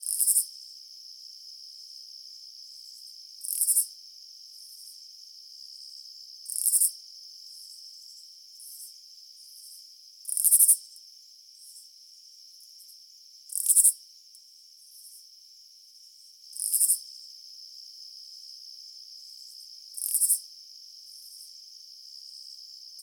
insectnight_11.ogg